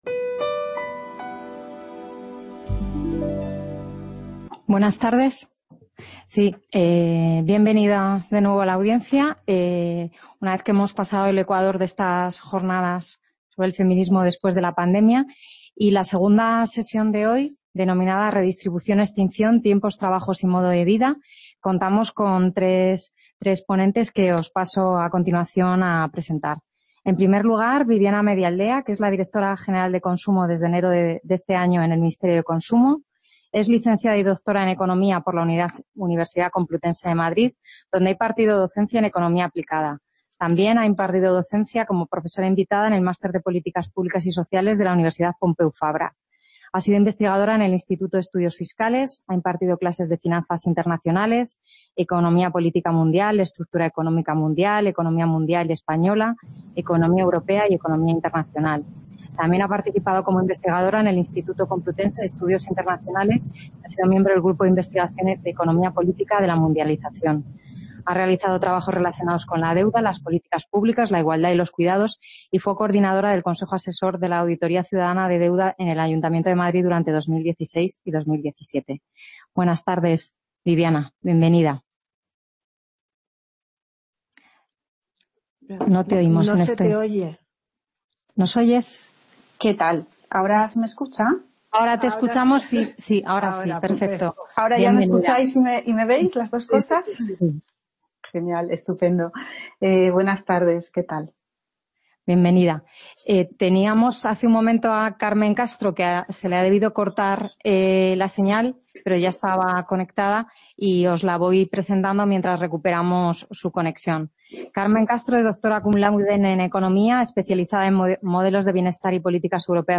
Conversatorio.
Redistribución o extinción: tiempos, trabajos y modos de vida (Varios Ponentes) Description El feminismo ha experimentado un aumento de su visibilidad y de su influencia en el último lustro.